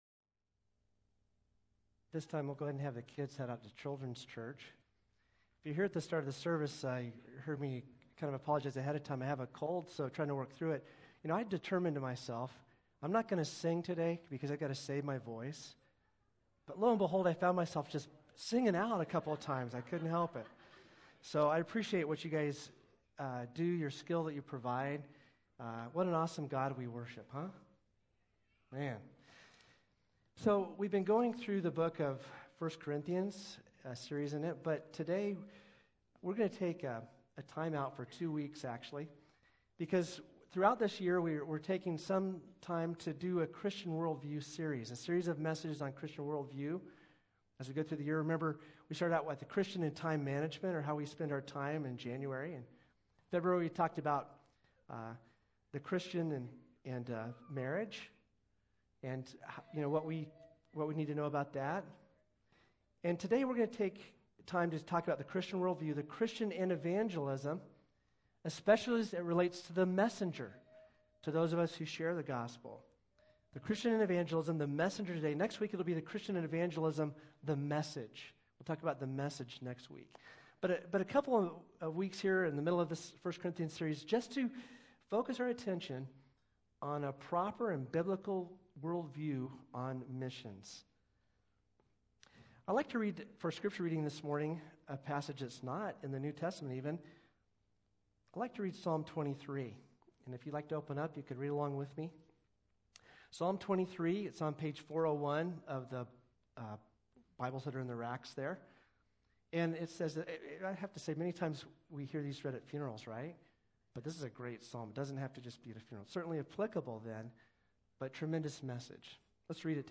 3-5-23-Sermon.mp3